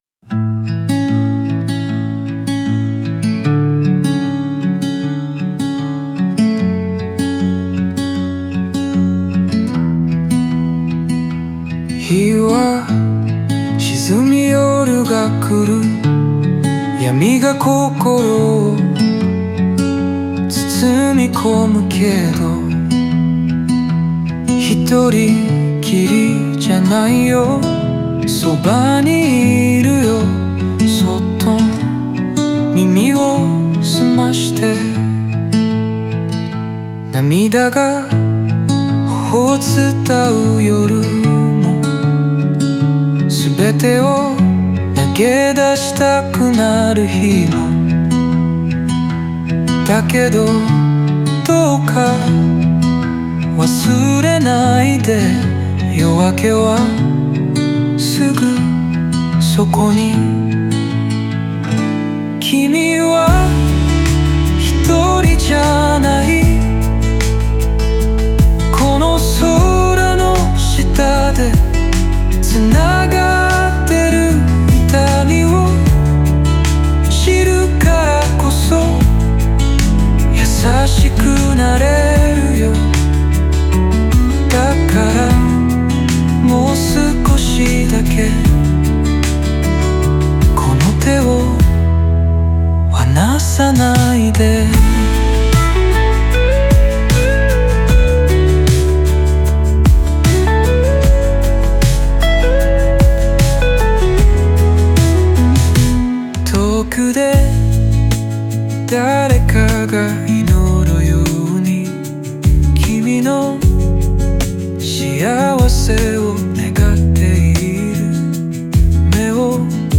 オリジナル曲♪
Music(音楽) Music(音楽) (1578)